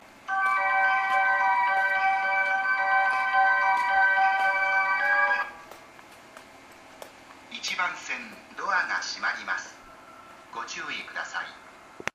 また当駅のスピーカーは小ボスが使用されており音質はいいです。
1番線宇都宮線
発車メロディー0.7コーラスです。